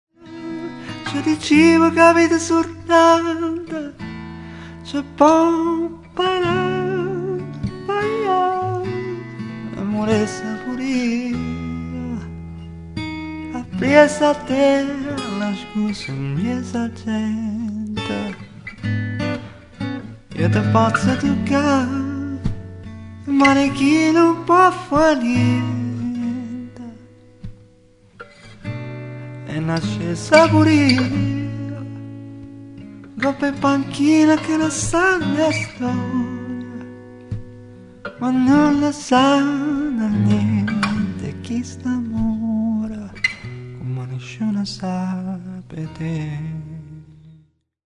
abbiamo montato un paio di microfoni
registrato e missato a 'la oficina' studio (bologna)